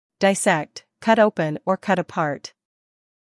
英音/ dɪˈsekt / 美音/ dɪˈsekt /